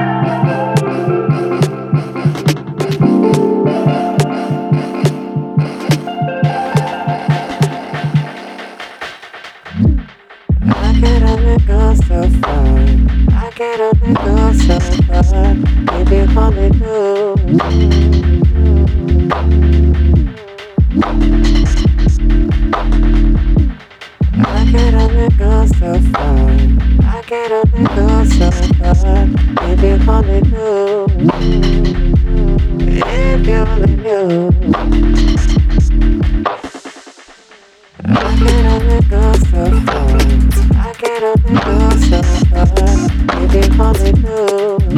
Electronix Hip Hop